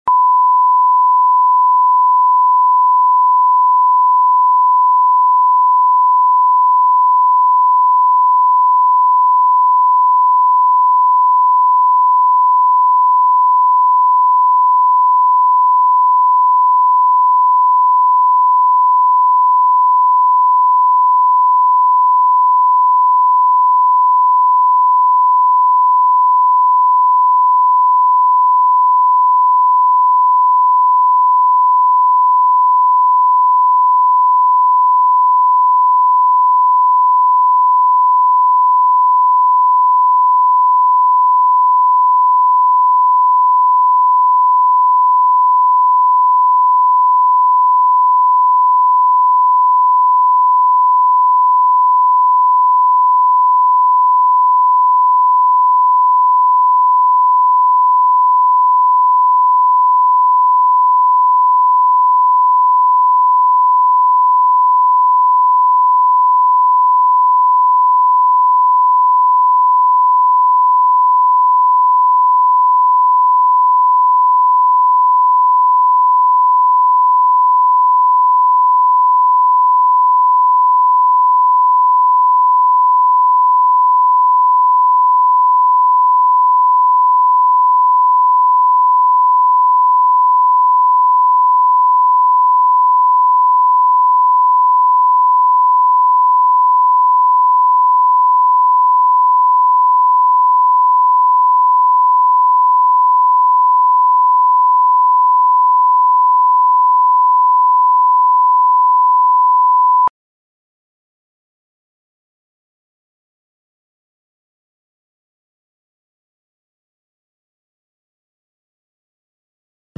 Аудиокнига В погоне за мечтой | Библиотека аудиокниг
Прослушать и бесплатно скачать фрагмент аудиокниги